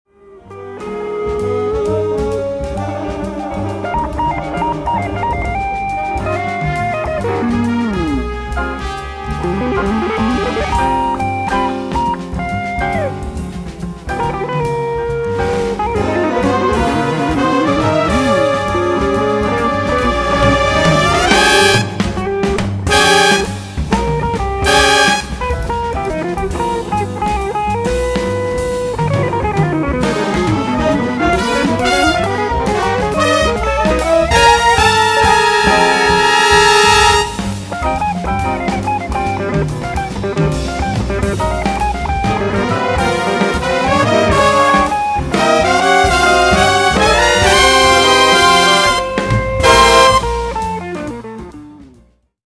Konzert für Gitarre und Jazz Orchester
Aufgenommen im ORF Studio RP2 im Jänner 2002